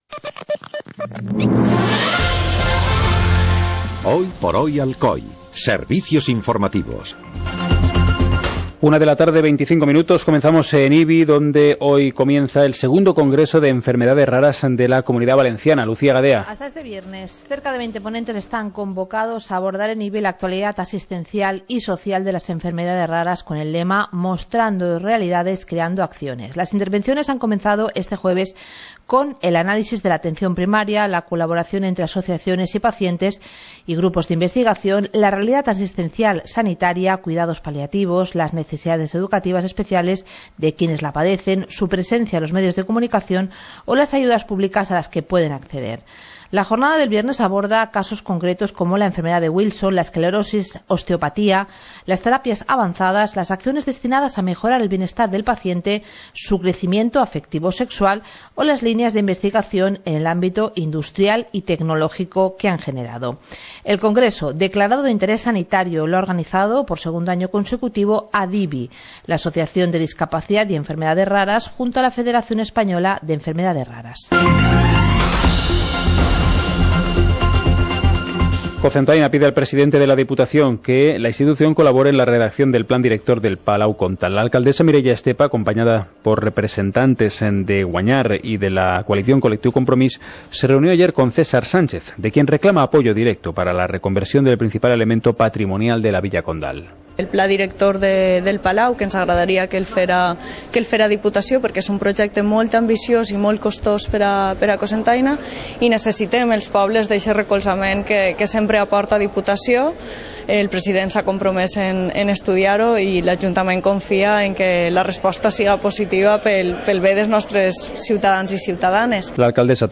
Informativo comarcal - jueves, 02 de junio de 2016